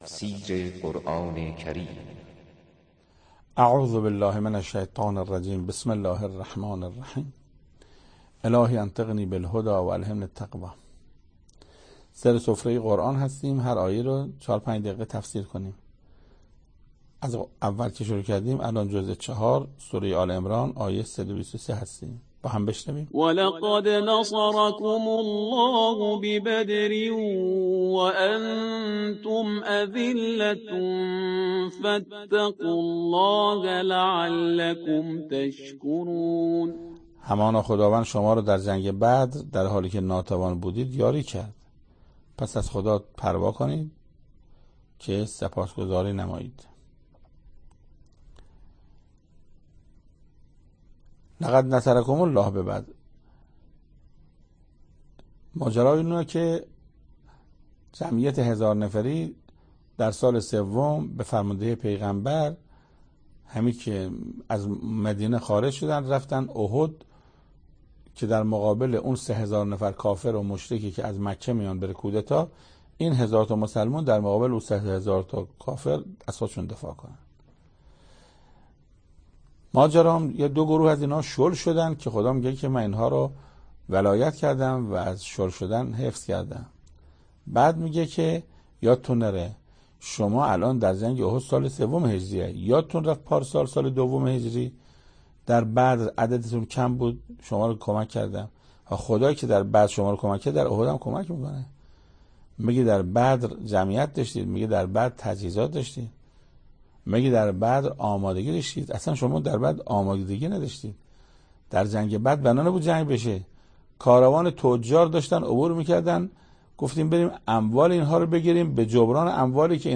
تفسیر صد و بیست و سومین آیه از سوره مبارکه آل عمران توسط حجت الاسلام استاد محسن قرائتی به مدت 6 دقیقه
سخنرانی محسن قرائتی